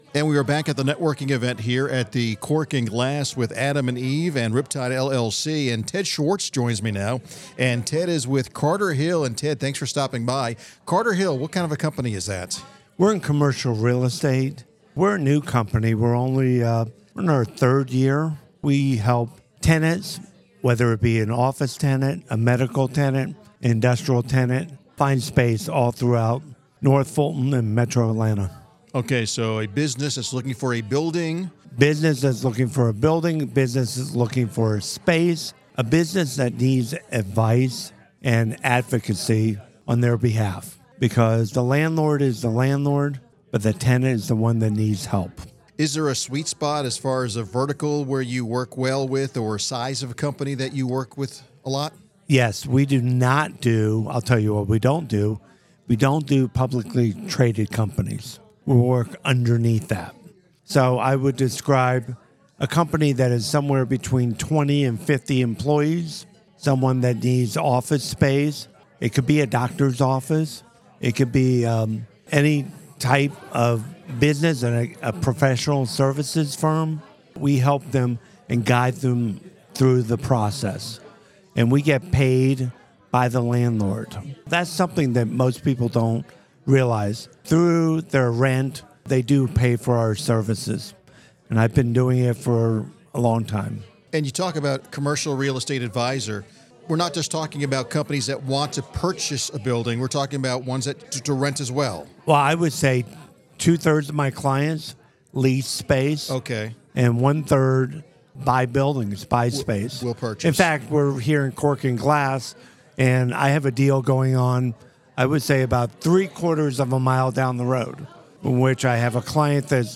Business RadioX was on site to interview and highlight several of the business professionals attending the event.